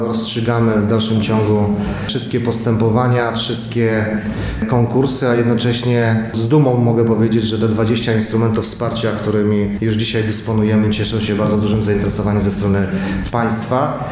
Jak podkreśla wicemarszałek województwa mazowieckiego Rafał Rajkowski wsparcie dla gmin i powiatów w dofinansowaniu jest niezwykle ważne: